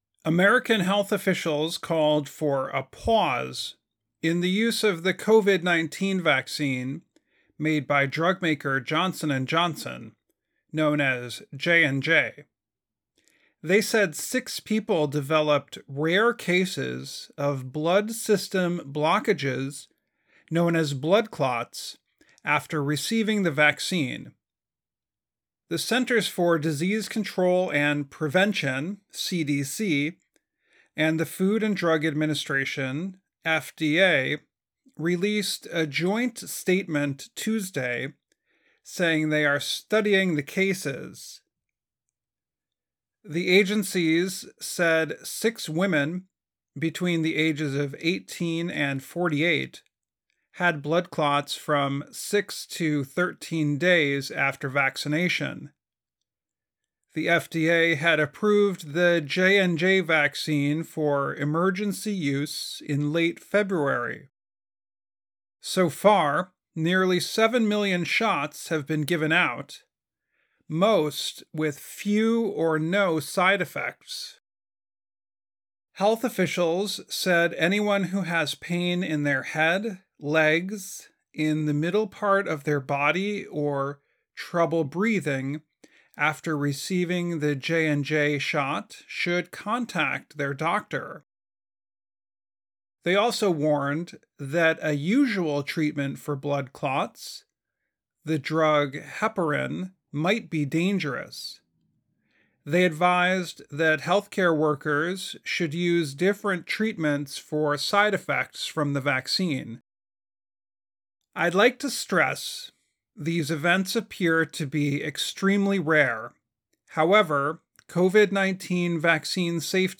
慢速英语:美国呼吁暂停使用强生公司的新冠疫苗